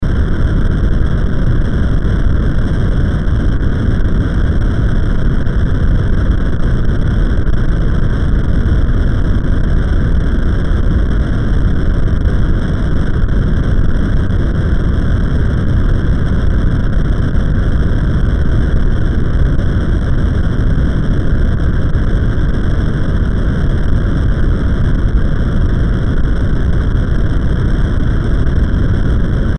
地響き 04(シンセ)
/ F｜演出・アニメ・心理 / F-50 ｜other アンビエント
ゴー